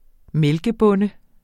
Udtale [ ˈmεlgə- ]